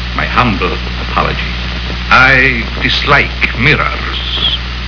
These various webpages pages were made solely for the purpose of enjoyment and entertainment for all online fans, as a friendly place to get their favorite classic horror sounds on wav.
Here's Bela Lugosi as The Count!